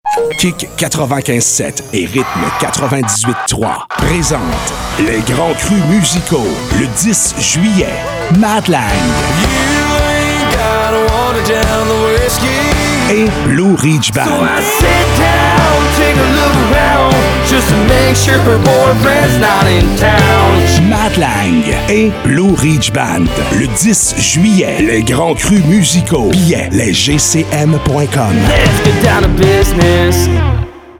PROMO RADIO MATT LANG & BLUE RIDGE BAND